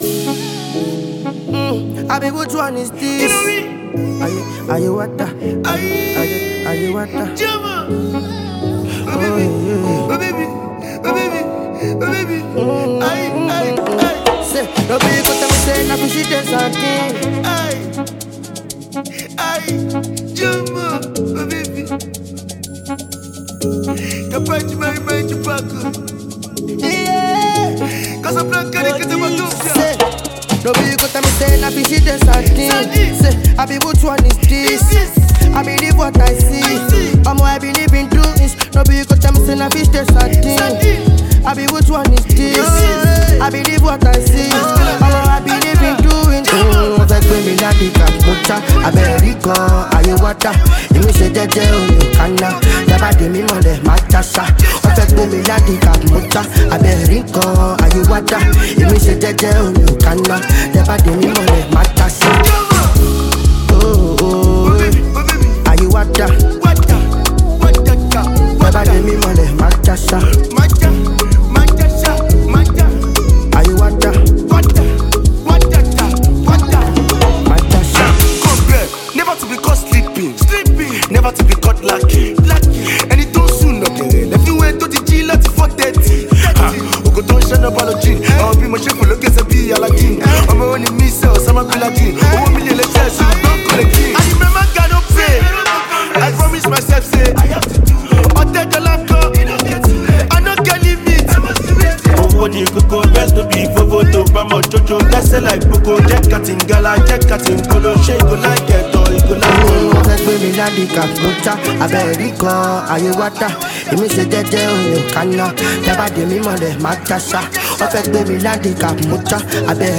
infused with Amapiano beats